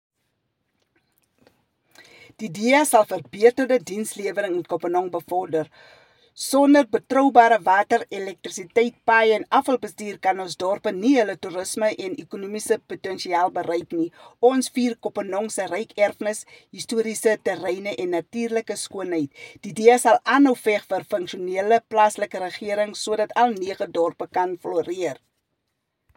Afrikaans soundbites by Cllr Hessie Shebe and